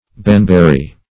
Baneberry \Bane"ber`ry\, n. (Bot.)